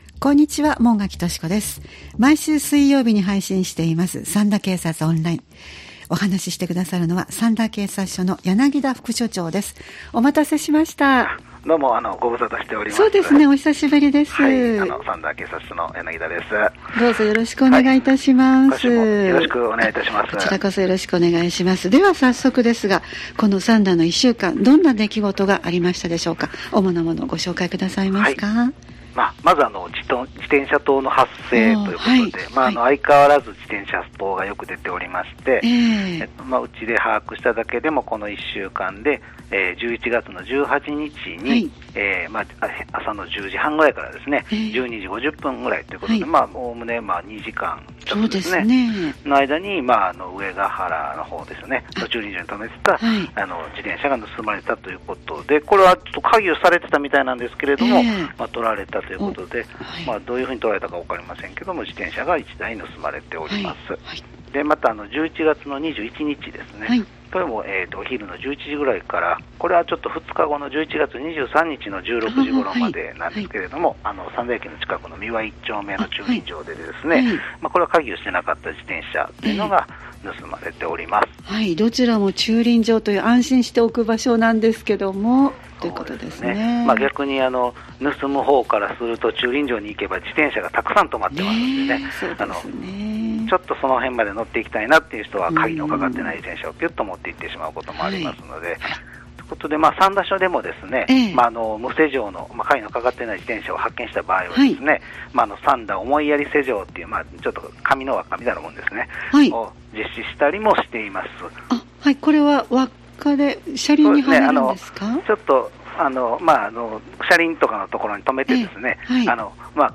三田警察署に電話を繋ぎ、三田で起きた事件や事故、防犯情報、警察からのお知らせなどをお聞きしています（再生ボタン▶を押すと番組が始まります）